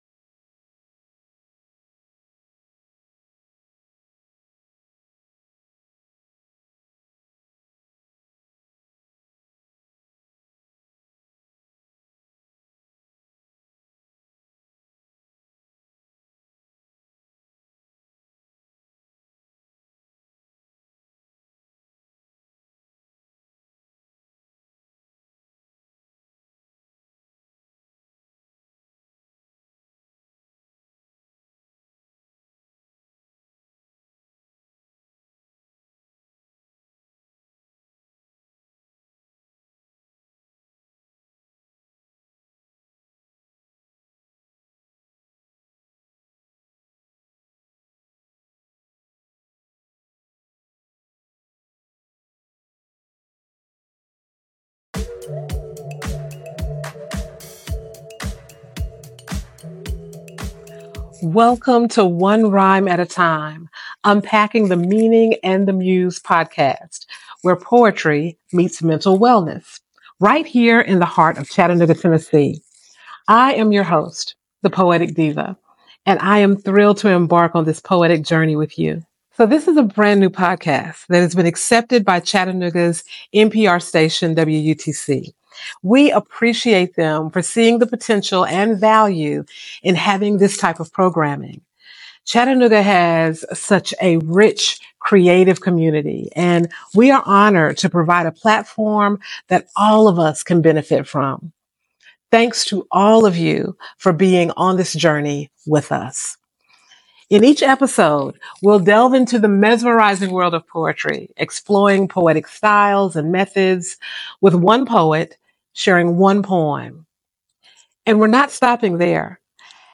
Episodes often explore specific emotional topics, like disappointment, with relevant poetry readings and discussions, exemplified by a recent episode featuring the poem 'Breaking Up With Lonely' and an expert discussion on coping strategies.